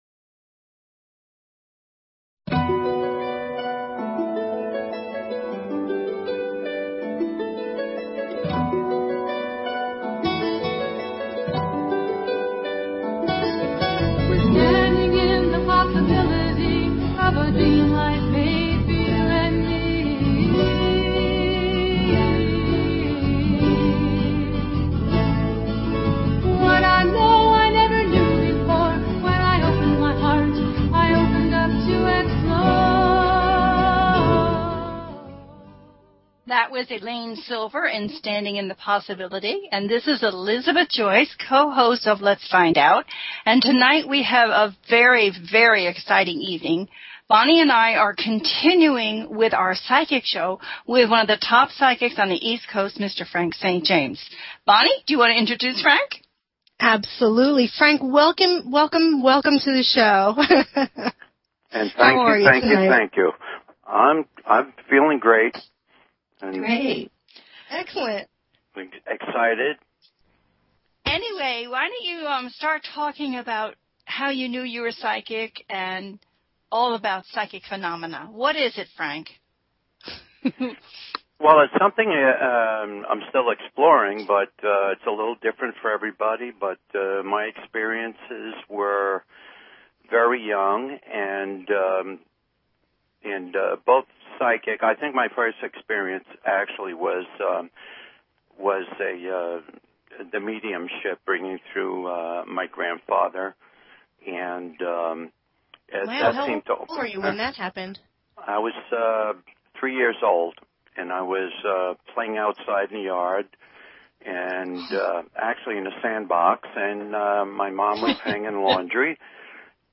Talk Show Episode
This is a call in show, so have your first name, birthday, and focused question ready. The show's information explains how a psychic gets their information and arrives at a prediction.